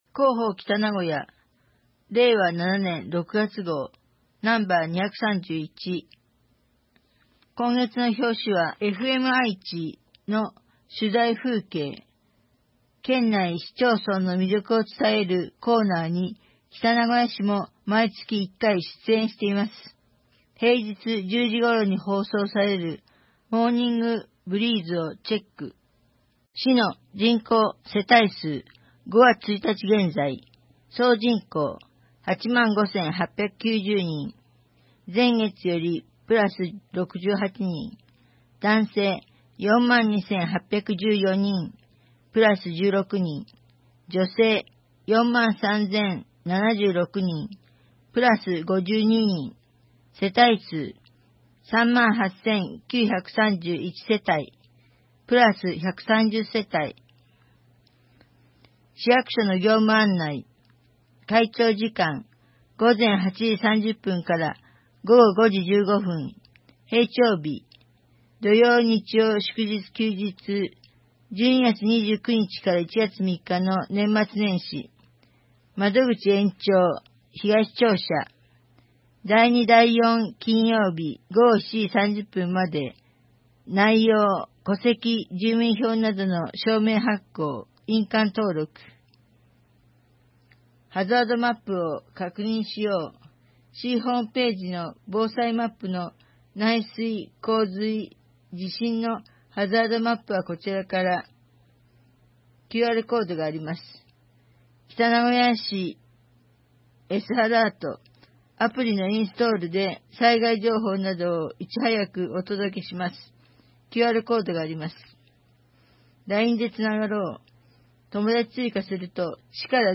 2025年6月号「広報北名古屋」音声版